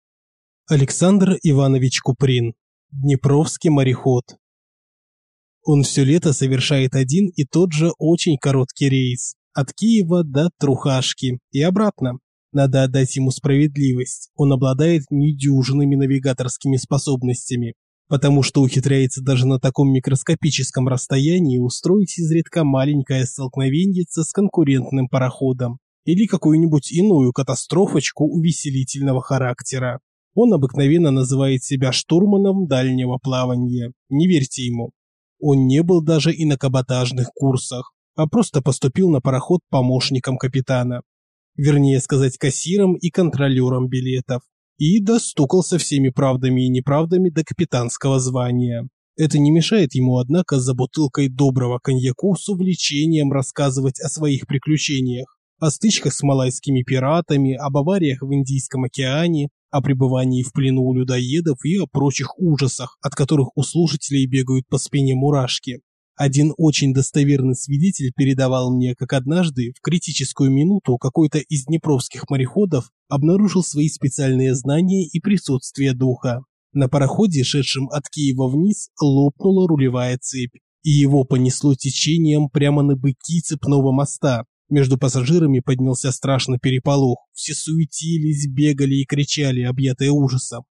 Аудиокнига Днепровский мореход | Библиотека аудиокниг